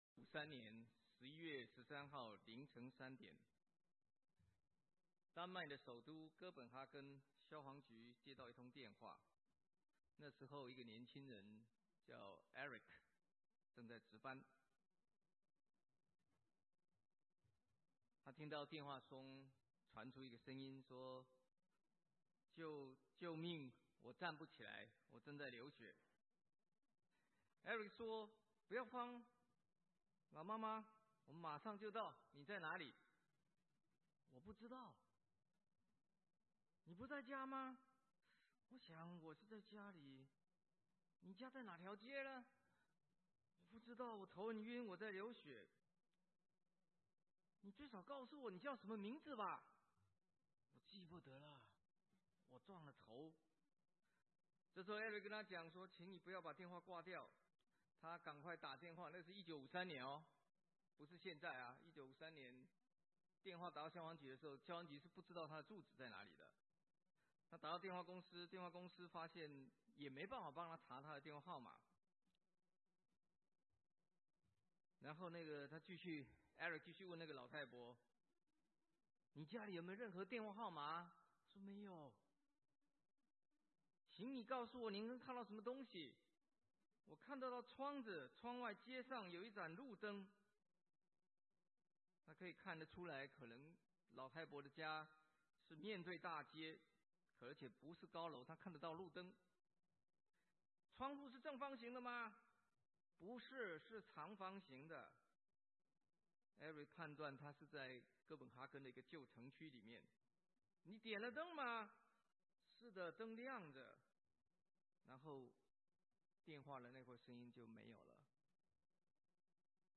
華埠國語堂